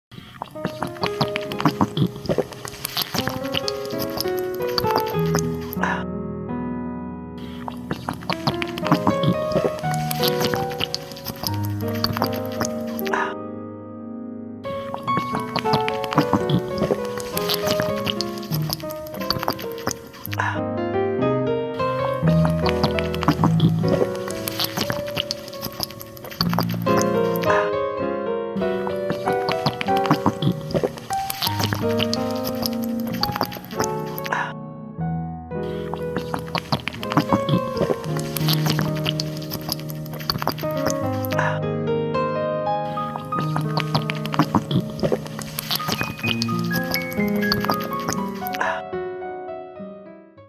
Random Rainbow Water Drink sound effects free download
Random Rainbow Water Drink Asmr Mukbang Animation Mukbang Animation Asmr Drinking Sunds